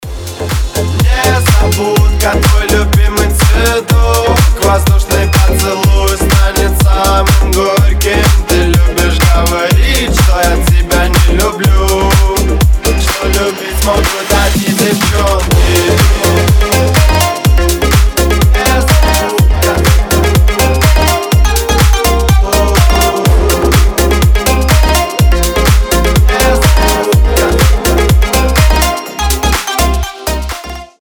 club house